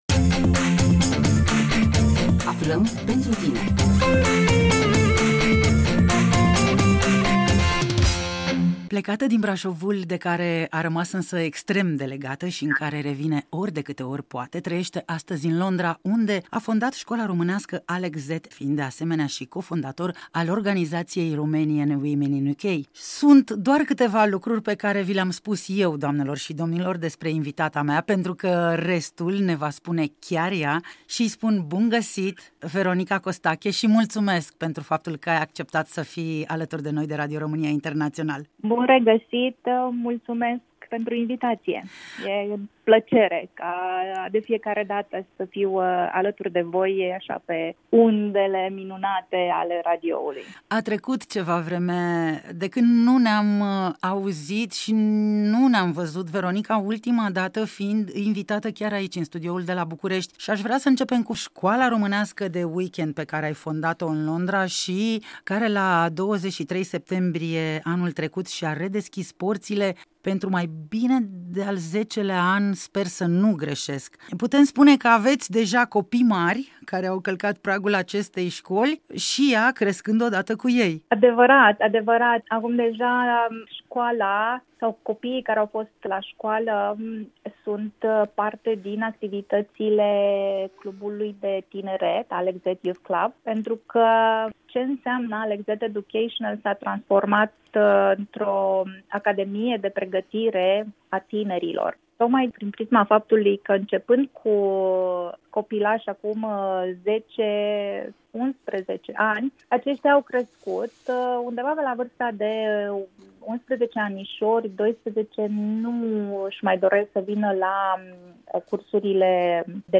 Formarea tinerilor prin cursuri de sprijin, autocunoaştere şi dezvoltare personală în cadrul şcolii de sâmbătă Alexz Educational din Londra. Interviu